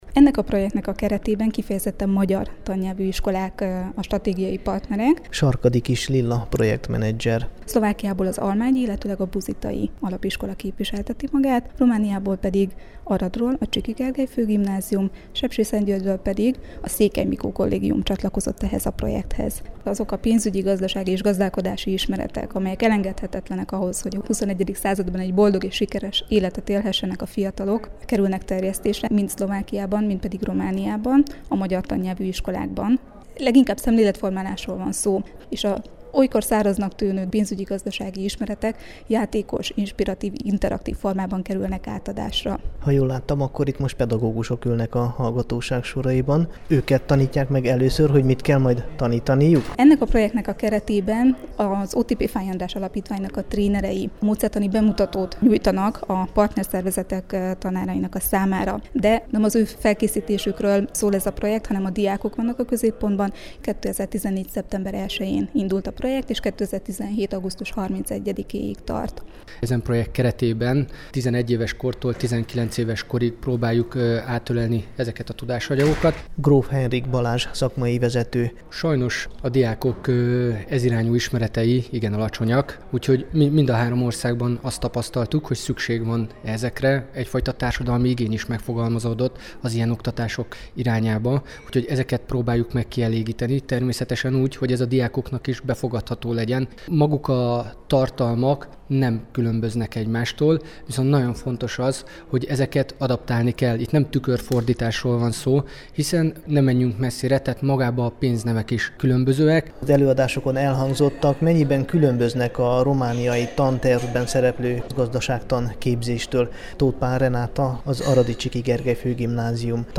kérdezte a szervezőket és a résztvevőket a Kossuth Rádió számára.